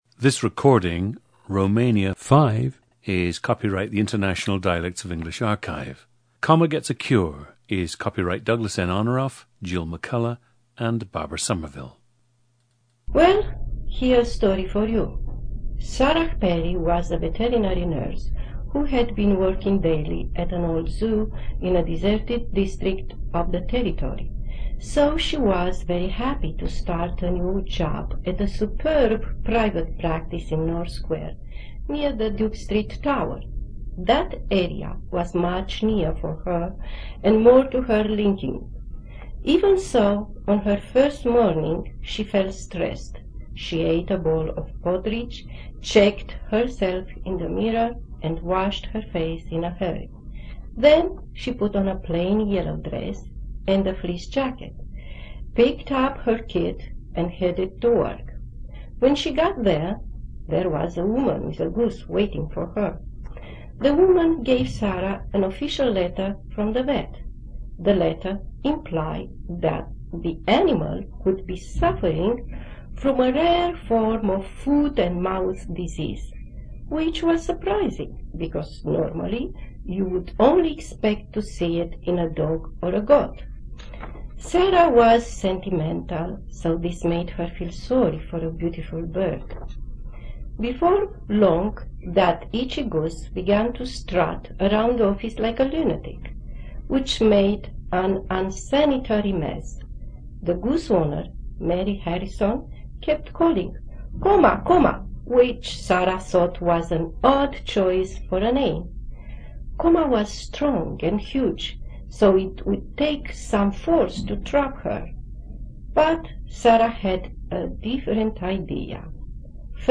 Listen to Romania 5, a woman in her 40s from Bucharest, Romania, who has also spent time in the United States.
GENDER: female
Some key features of her accent include lightly tapped or flapped [r], [k] for [g], [i] for [I], and [s] for [th]. The “-ing” suffix tends to shift to “-ink” with little or no explosion on the final [k].
Many final consonants are stopped but not exploded. Subject also exhibits altered stress.
The recordings average four minutes in length and feature both the reading of one of two standard passages, and some unscripted speech.